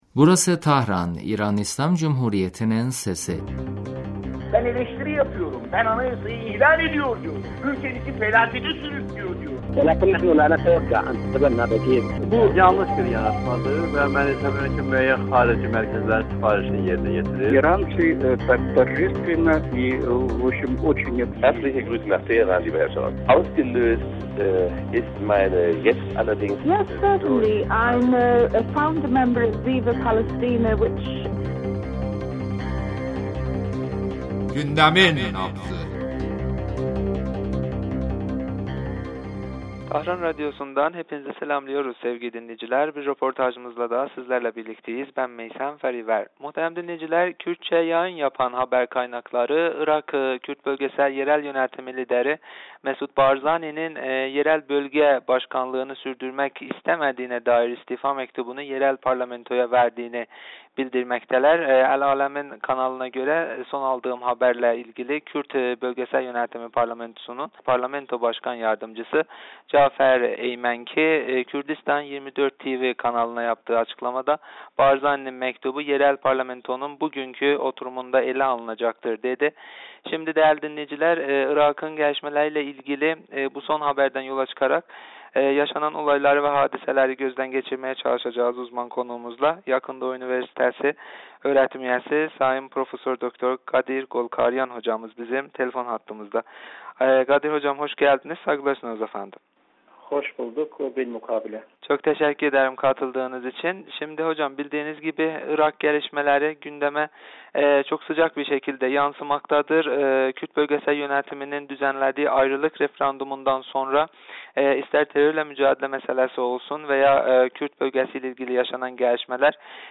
radyomuza verdiği demecinde Barzani'nin istifası ve Kuzey Irak'tan bölgemize yansıyan gelişmelerle ilgili görüş ve fikirlerini paylaştı.